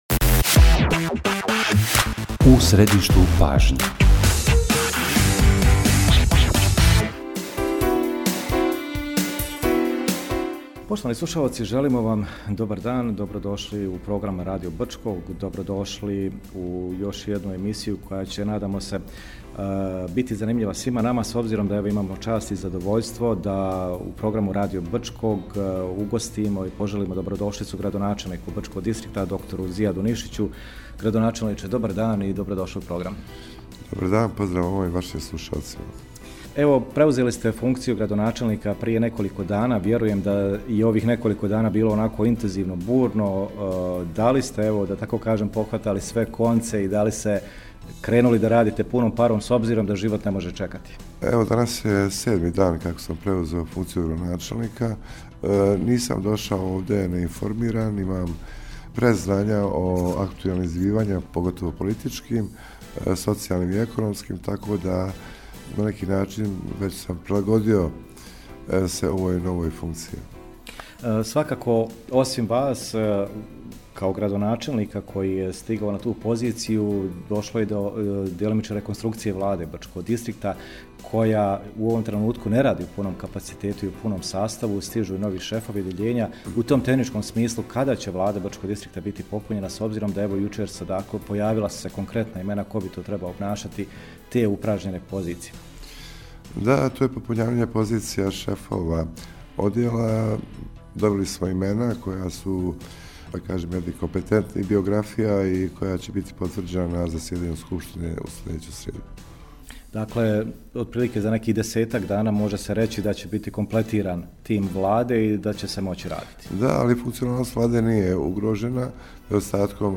Gost emisije “U središtu pažnje” gradonačelnik Brčko distrikta BiH prim. dr. Zijad Nišić